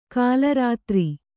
♪ kālarātri